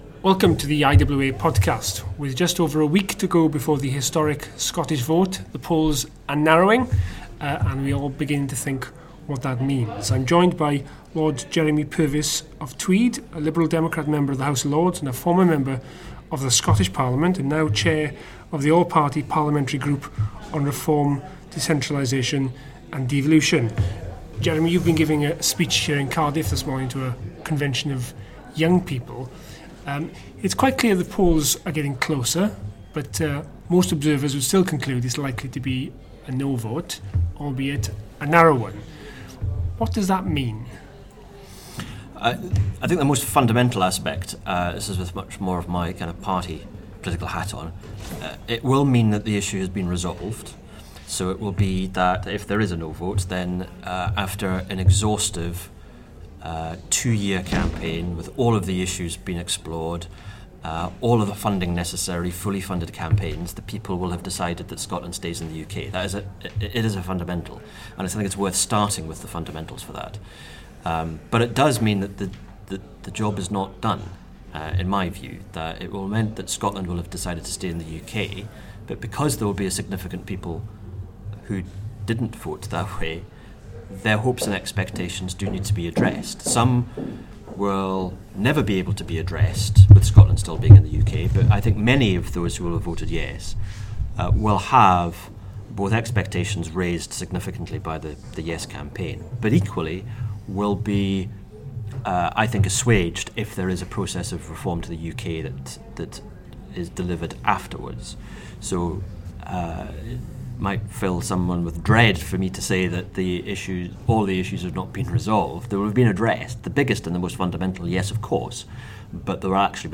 IWA Director Lee Waters discusses the potential fallout from the Scottish referendum campaign with Lord Jeremy Purvis of Tweed, Chair of the All-Party Parliamentary group on Reform, Decentralisation and Devolution.